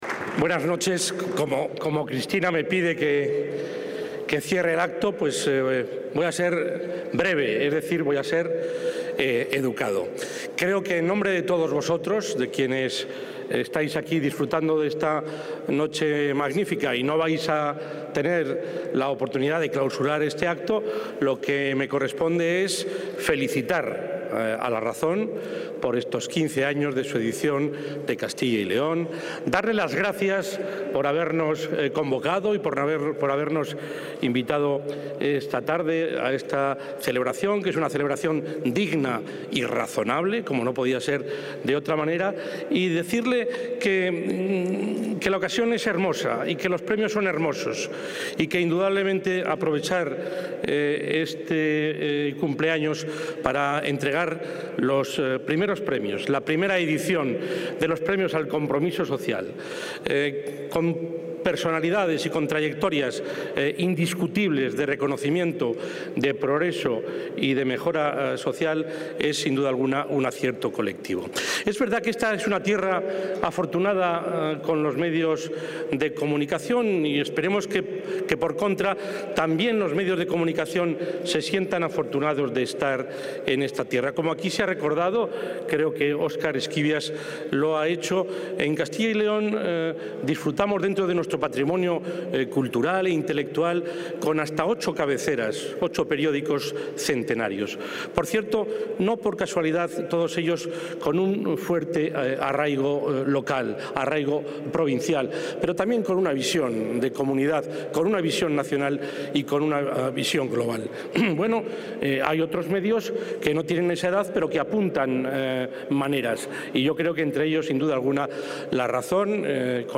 El presidente Herrera clausura el acto de celebración del XV aniversario de La Razón y de entrega de los Premios al Compromiso Social.